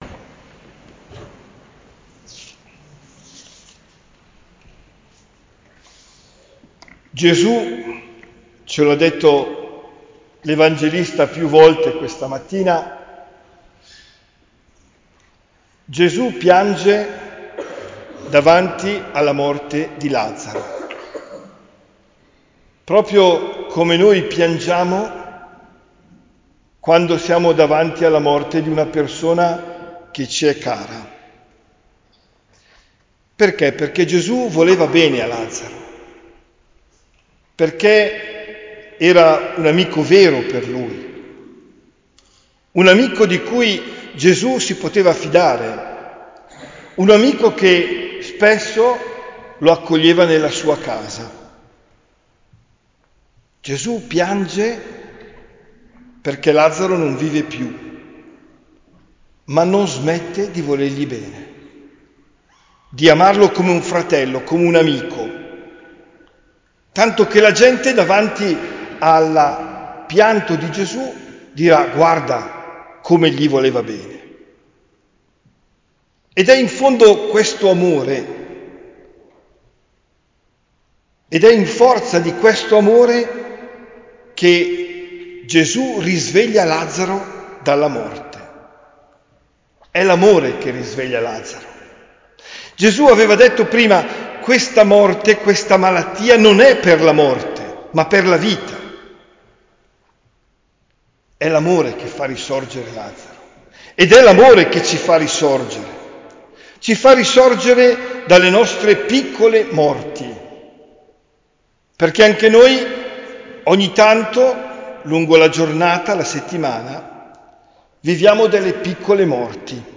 OMELIA DEL 26 MARZO 2023